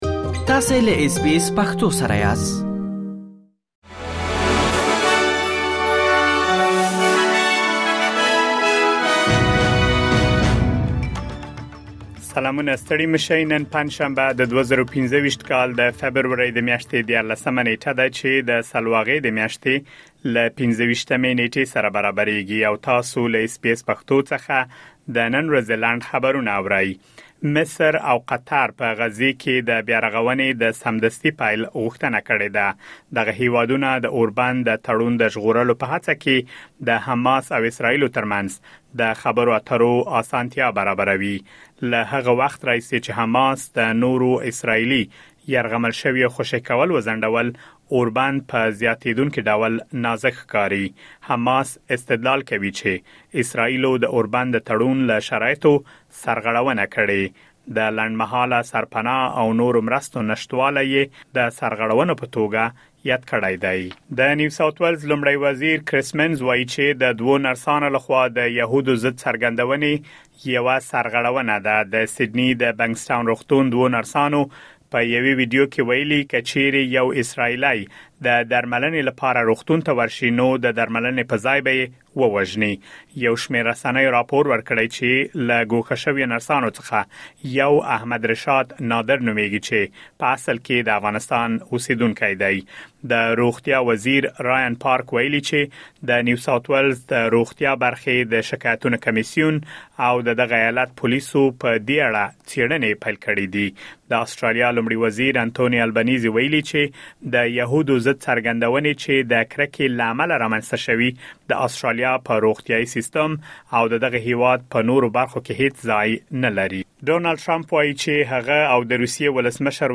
د اس بي اس پښتو د نن ورځې لنډ خبرونه | ۱۳ فبروري ۲۰۲۵